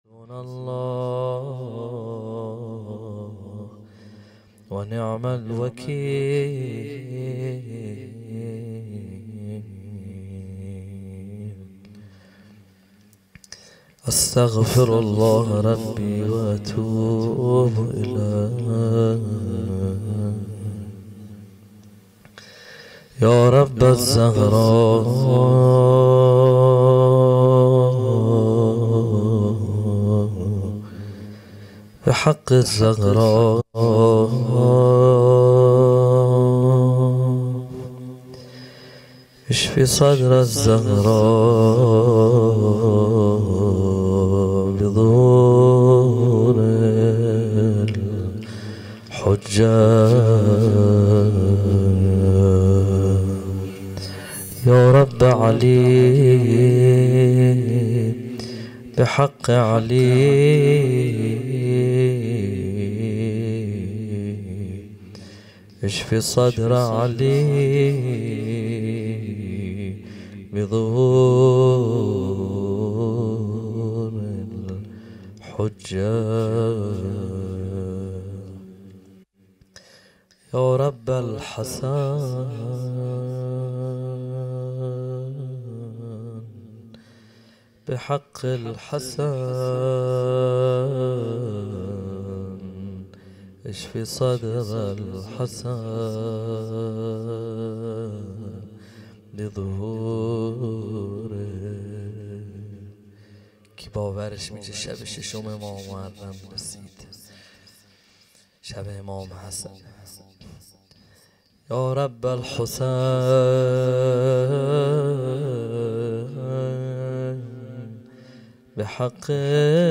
روضه | هر که از، عاشقی خبر دارد در دلش میل ترک سر دارد
شب ششم محرم الحرام 1442 ه.ق | هیأت علی اکبر بحرین